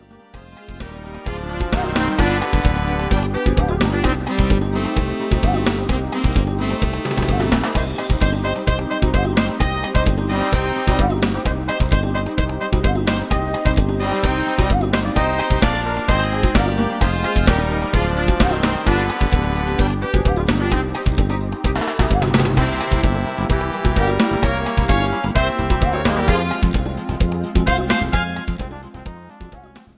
(instrumtal Orchester)